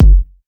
Chart Kick 03.wav